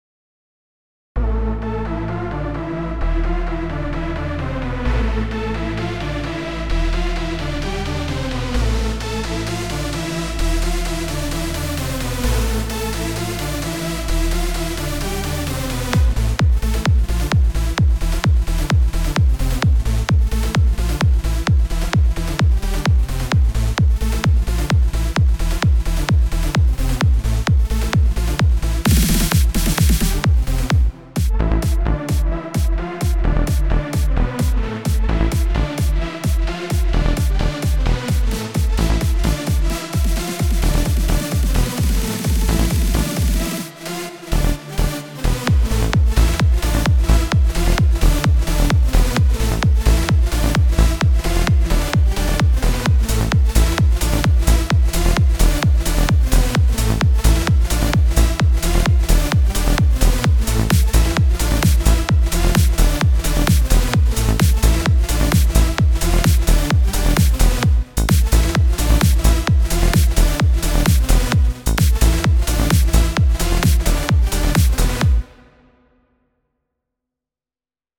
כי בעיקרון זה הולך שבקטע השני זה אותו דבר רק שעם הפיצ’ אתה מעלה עד לאוקטבה הבאה.